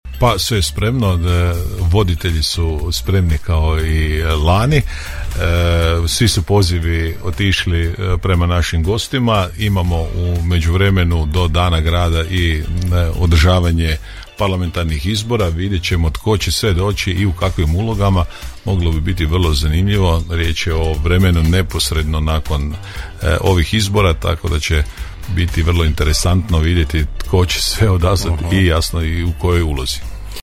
Gradonačelnik Grada Đurđevca Hrvoje Janči, u Gradskim je temama Podravskog radija otkrio hoće li radovi biti gotovi do Dana grada, a otkrio je i novitet u promjeni jednog biračkog mjesta u centru grada obzirom da se datum Parlamentarnih izbora poklapa i programom Đurđeva;